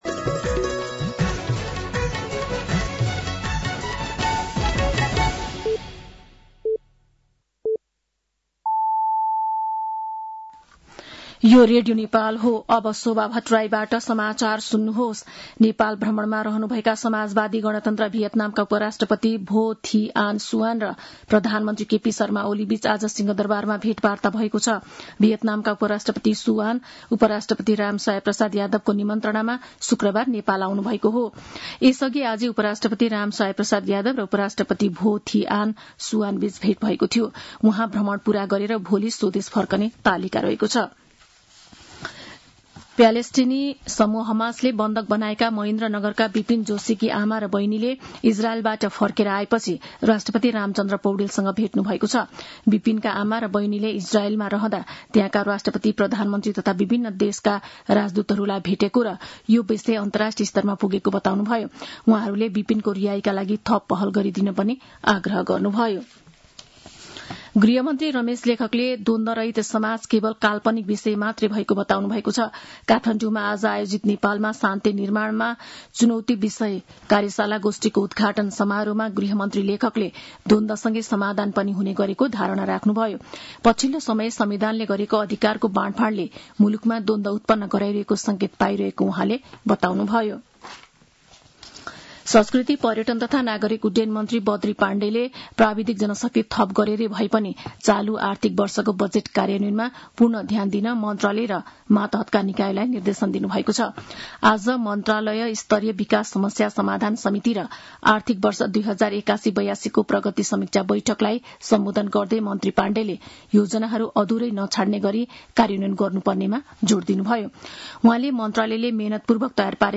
साँझ ५ बजेको नेपाली समाचार : ८ भदौ , २०८२